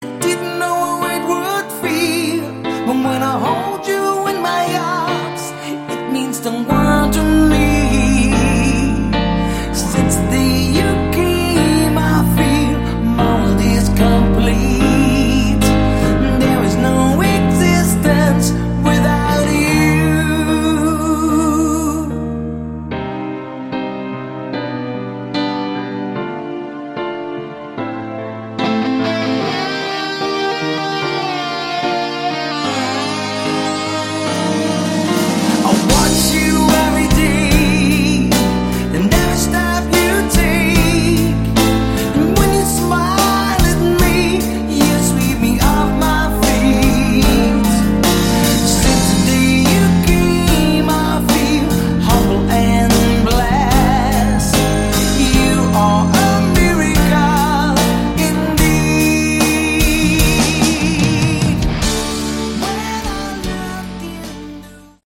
Category: AOR
The piano ballads